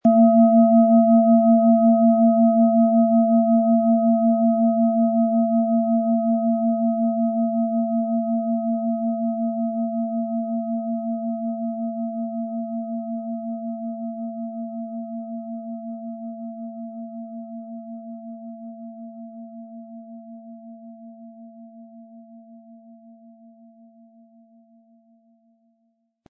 Planetenton 1
Es ist eine von Hand getriebene Klangschale, aus einer traditionellen Manufaktur.
Aber dann würde der ungewöhnliche Ton und das einzigartige, bewegende Schwingen der traditionellen Herstellung fehlen.
Im Preis enthalten ist ein passender Klöppel, der die Töne der Schale gut zum Schwingen bringt.
MaterialBronze